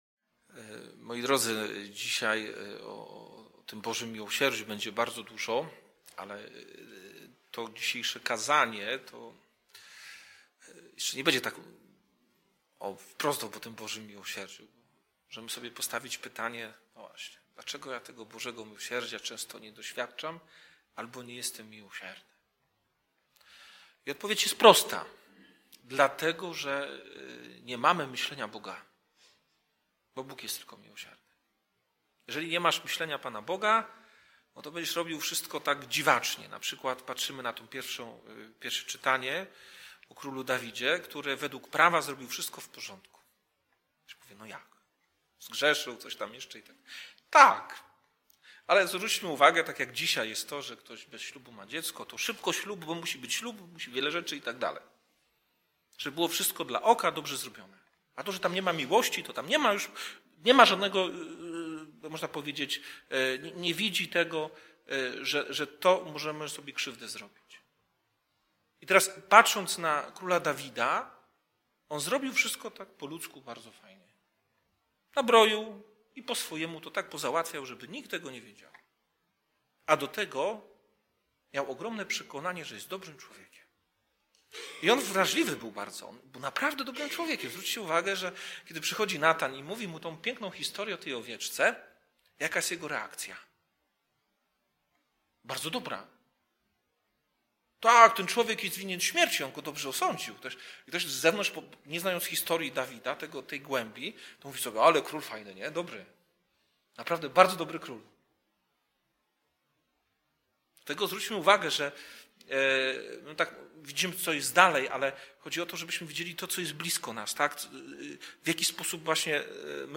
Homilia - 2 Sm 12,1-7a.10-17; Mk 4,35-41